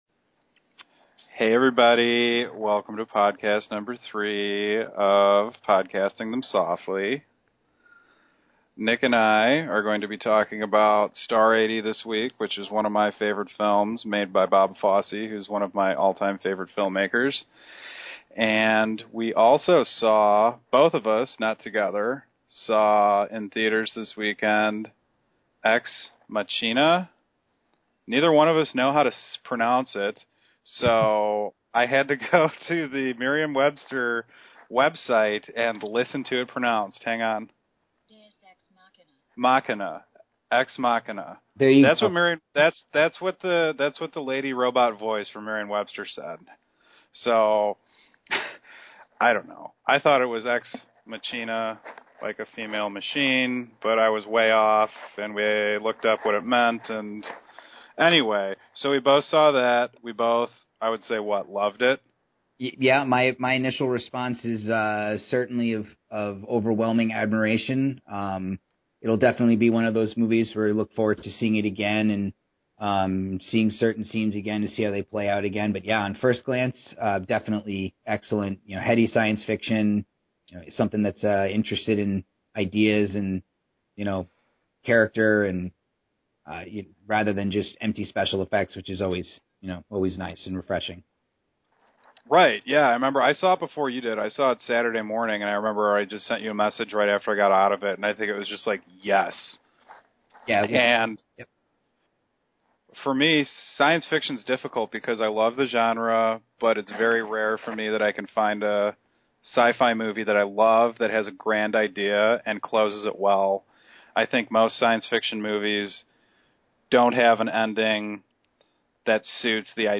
Actor’s Spotlight with DAVID CLENNON
We have a very special guest, someone who needs no introduction.